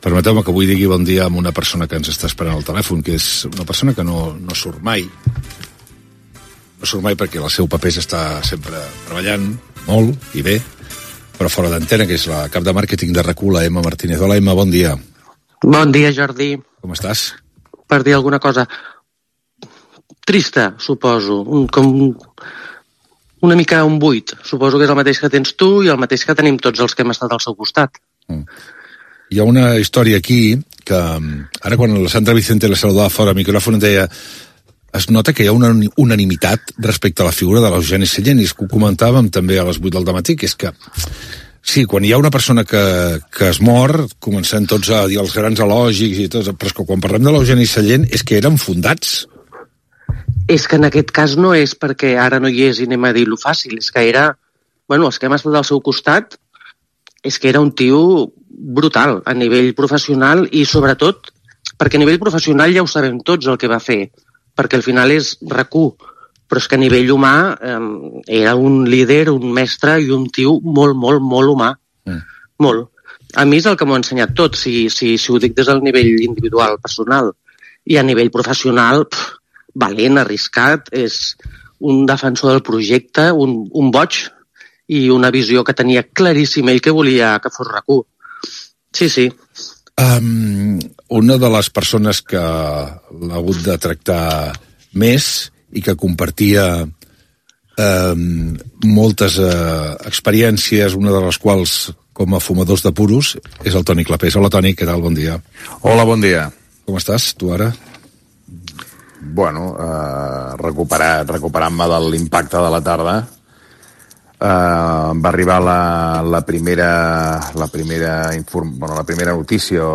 Gènere radiofònic Info-entreteniment
Banda FM